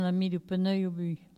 Localisation Châteauneuf
Catégorie Locution